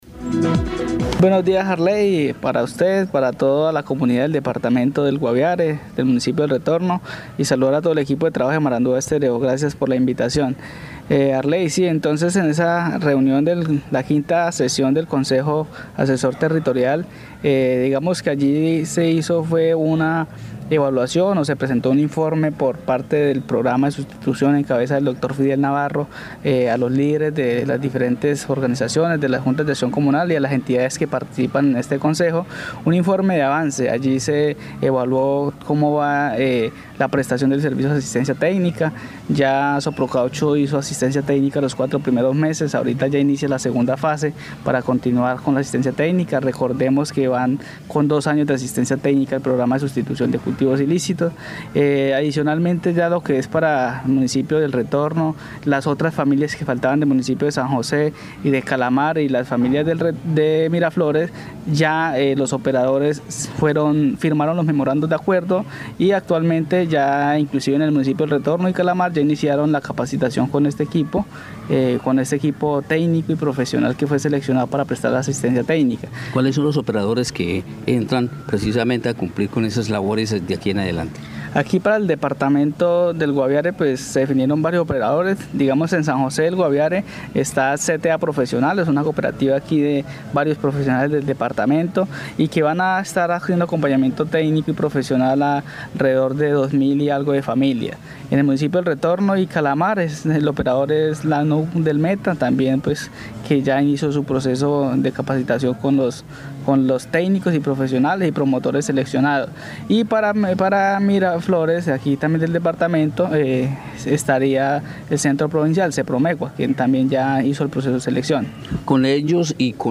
Instalación de la V Sesión del Consejo Asesor Territorial del P-NIS Guaviare y sur del Meta,